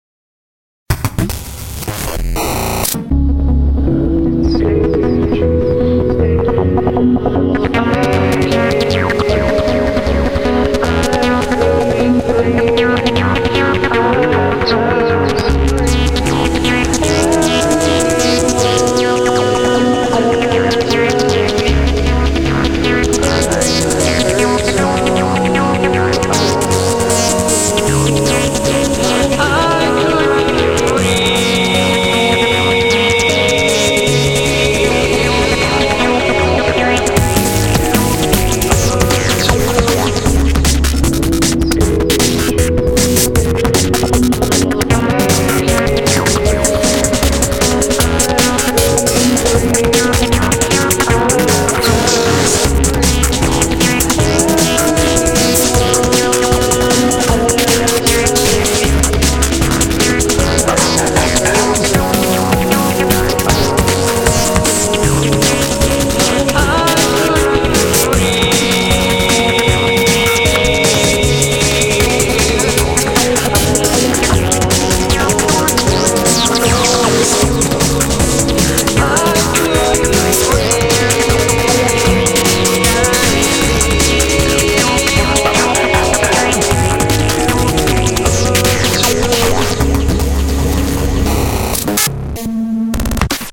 BPM155
Audio QualityPerfect (High Quality)